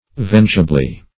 -- Venge"a*bly , adv.